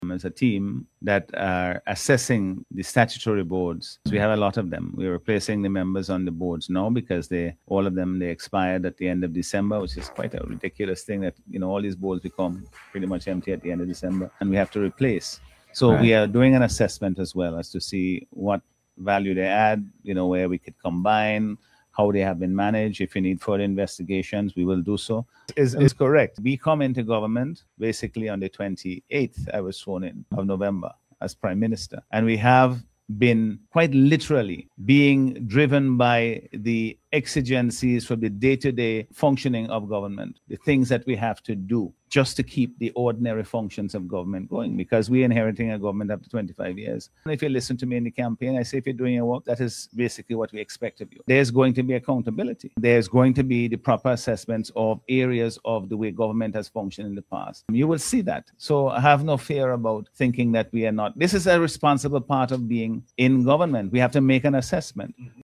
Speaking on local radio last week, Dr. Friday explained that a team is evaluating the effectiveness of each Board, looking for opportunities to combine or improve them, and ensuring accountability in government operations.